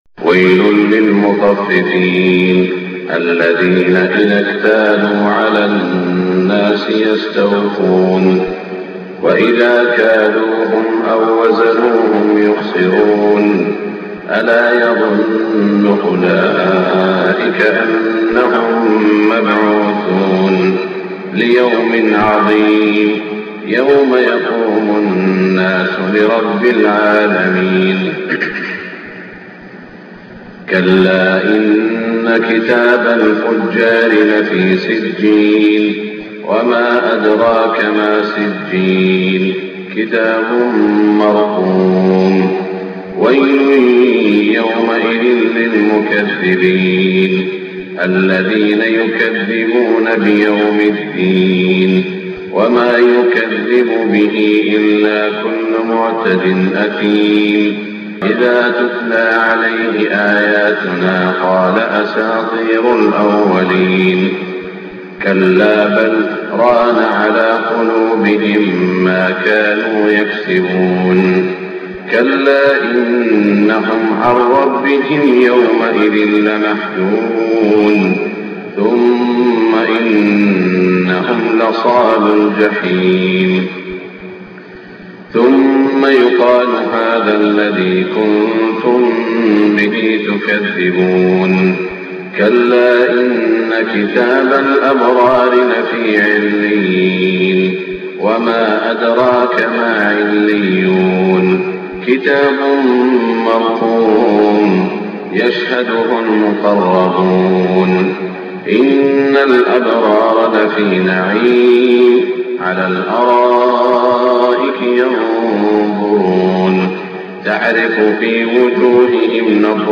صلاة الفجر 3-4-1427 سورتي المطففين و البينة > 1427 🕋 > الفروض - تلاوات الحرمين